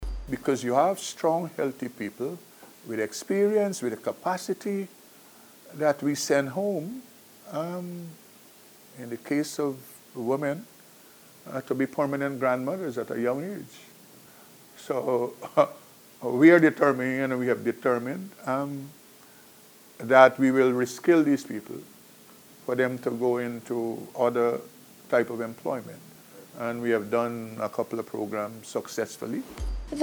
In an exclusive interview with NCN, Minister Hamilton disclosed that ongoing construction projects in Region 6, including New Amsterdam and Corentyne, and Unity Mahaica will be complemented by the establishment of new facilities in Region 1, Port Kaituma, and Region 3, Parfait Harmony.